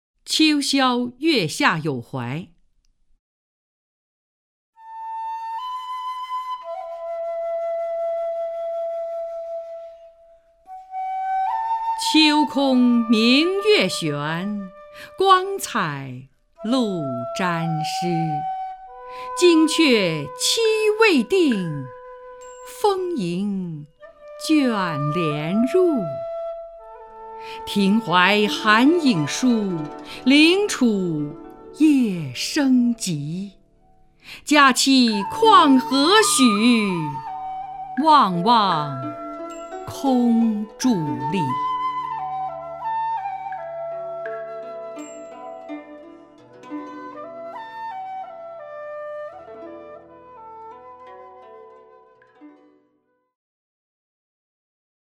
首页 视听 名家朗诵欣赏 张筠英
张筠英朗诵：《秋宵月下有怀》(（唐）孟浩然)